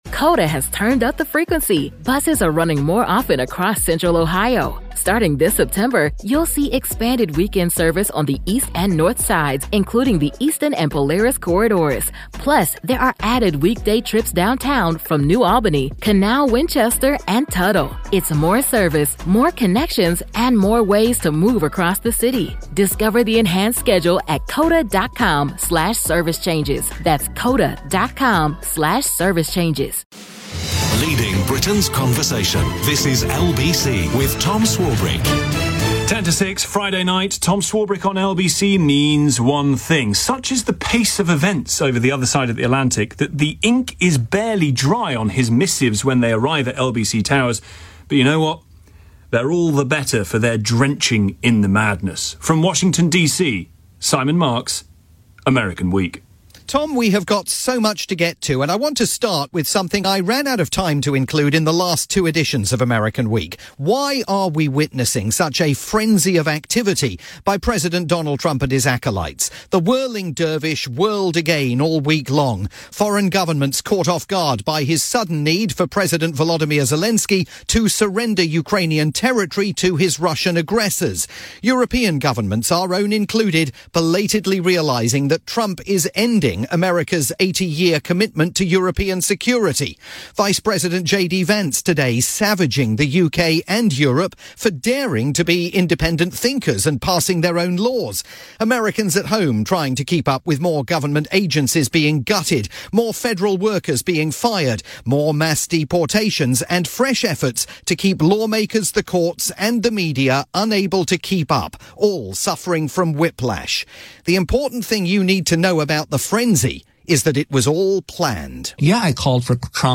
weekly chronicle of events in the United States, for Tom Swarbrick's drivetime programme on the UK's LBC.